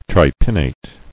[tri·pin·nate]